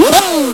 ENGREV.WAV